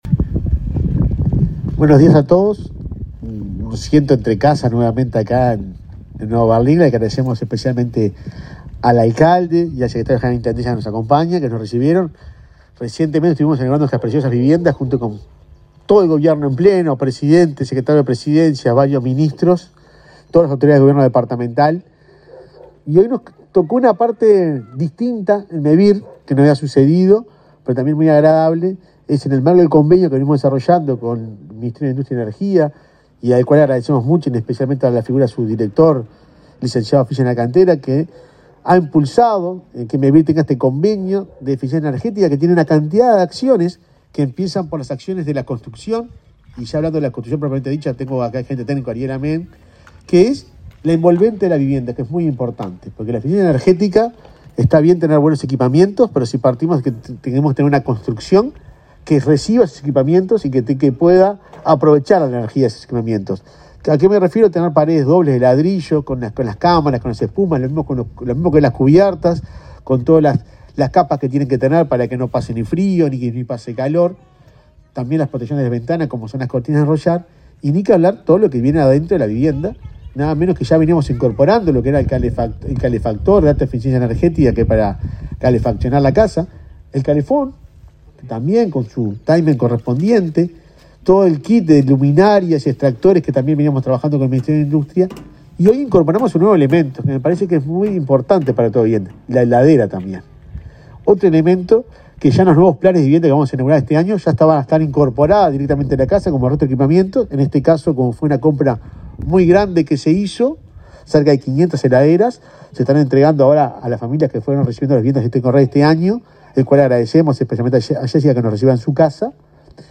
Palabra de autoridades en acto de Mevir y Ministerio de Industria
El presidente de Mevir, Juan Pablo Delgado, y el director nacional de Energía, Fitzgerald Cantero, participaron del acto de entrega de equipamiento